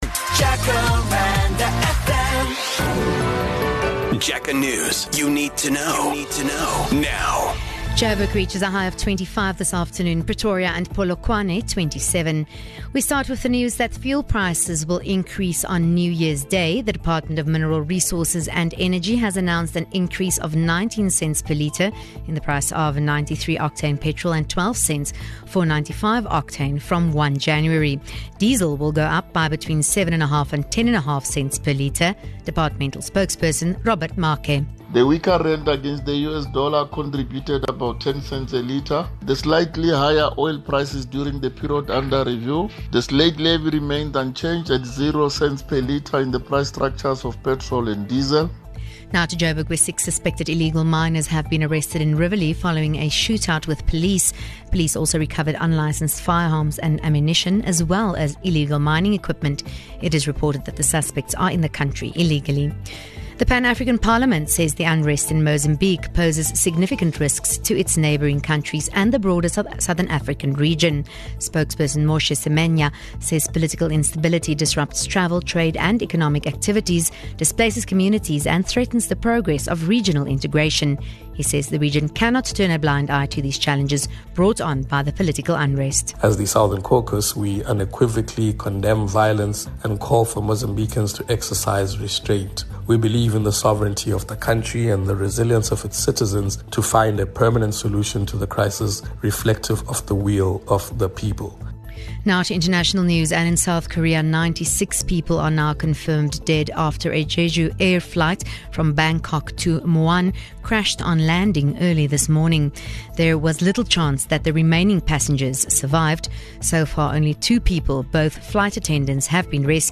Here's your latest Jacaranda FM News bulletin.